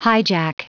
Prononciation du mot hijack en anglais (fichier audio)
Prononciation du mot : hijack